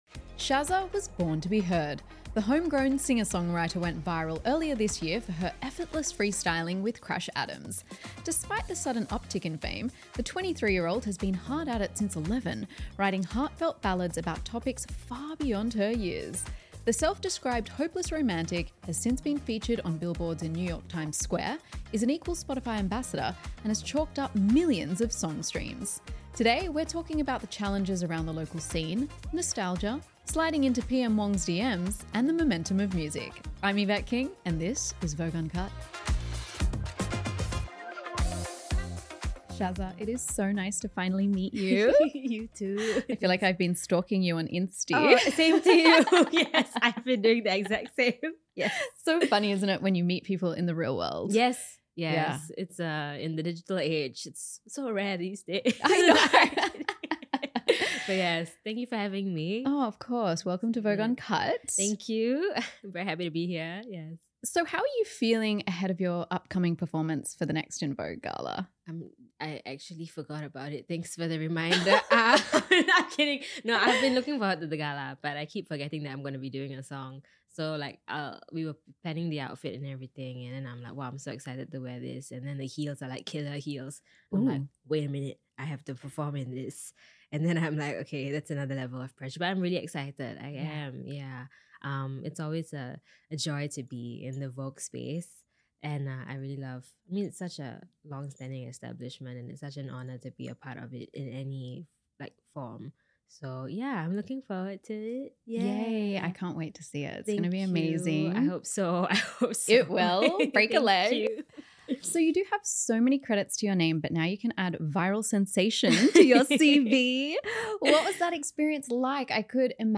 In conversation